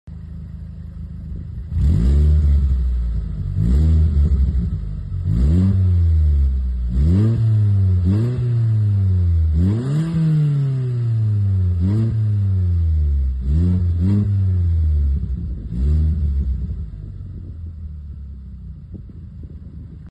Audi A3 8L Novus Sport Exhaust